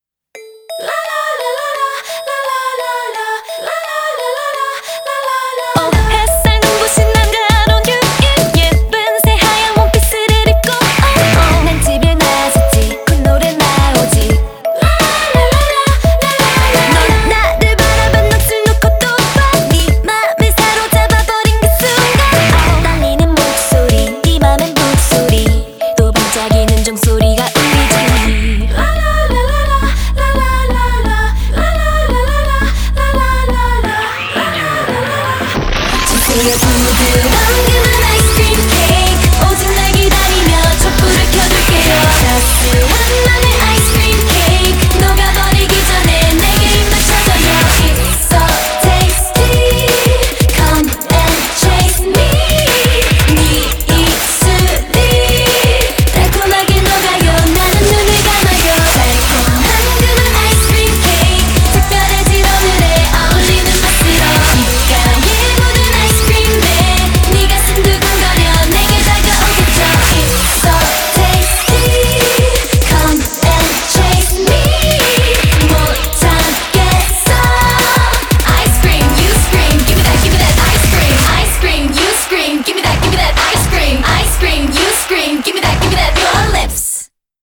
BPM86
Audio QualityPerfect (High Quality)